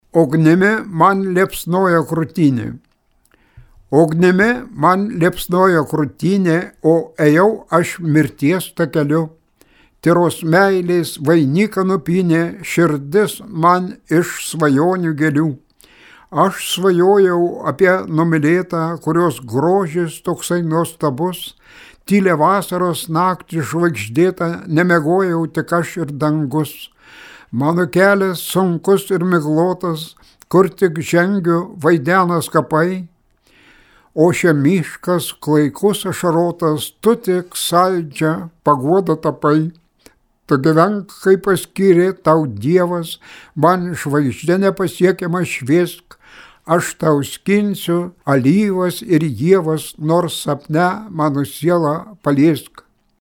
Eilės
eilių, jo paties skaitomų